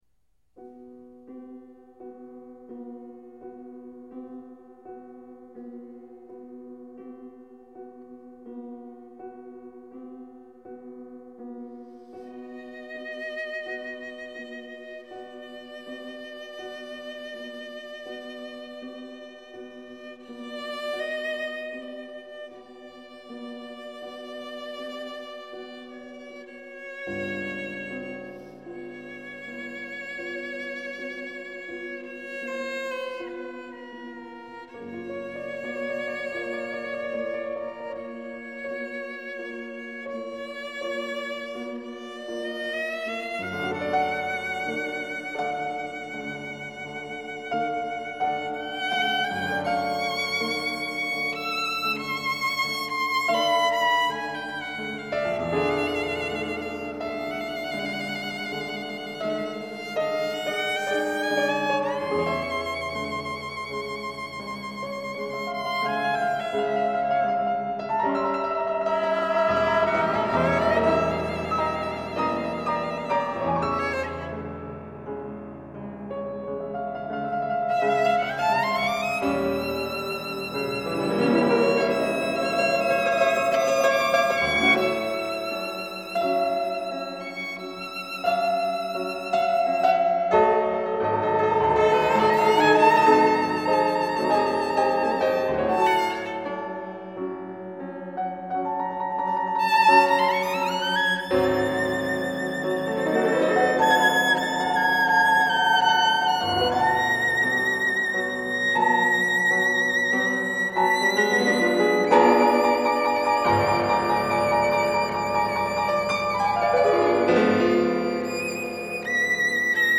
op. 11 för violin och 2 pianon stämda en kvartston isär